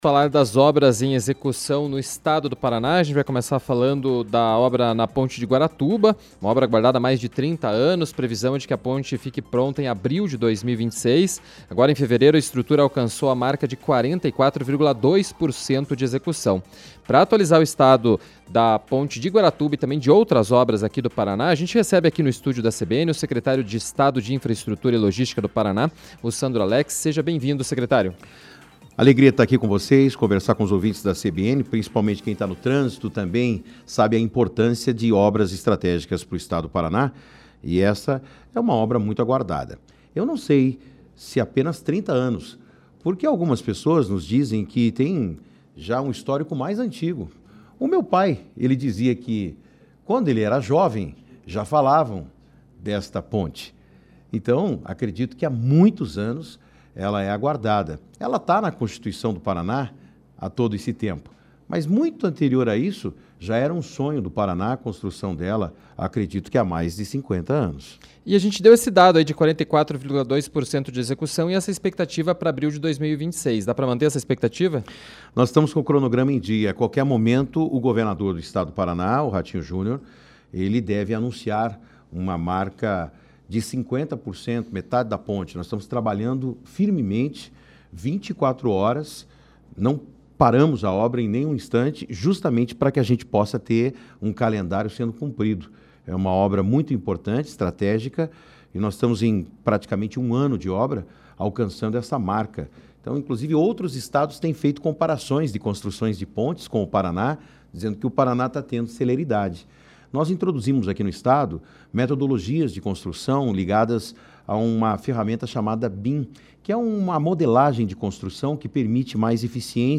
A afirmação foi dada pelo Secretário de Estado de Infraestrutura e Logística do Paraná, Sandro Alex, em entrevista à CBN Curitiba. Ele também falou sobre as obras na Ponte de Guaratuba, das trincheiras da Linha Verde e comentou sobre a expectativa com relação a concessão dos dois últimos lotes do pedágio no Paraná.